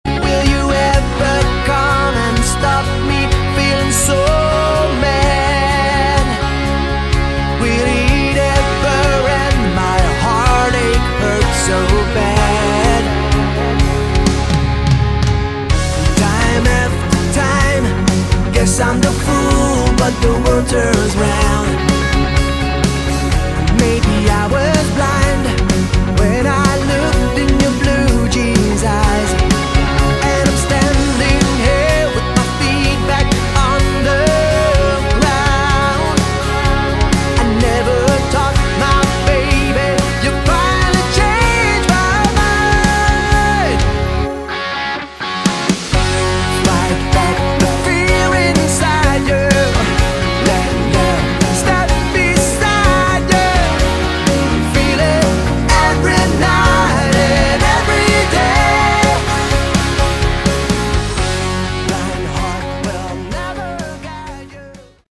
Category: AOR / Melodic Rock
vocals, guitar
bass
keyboards
drums